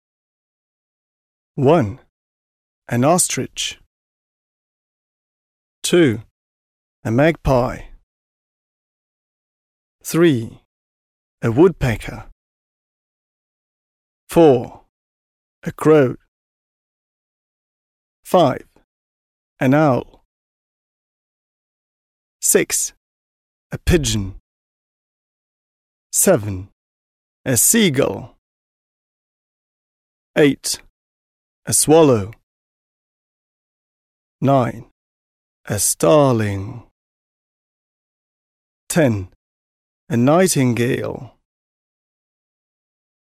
В слове woodpecker ударение падает на первый слог, окончание -er читается как нейтральный звук [ə] (похожий на нечеткое «э»).
Слово nightingale состоит из трех слогов, где сочетание букв igh читается как дифтонг [aɪ] (ай).
Слово pigeon содержит звук [ʤ], который звучит мягко, как слитное «дж».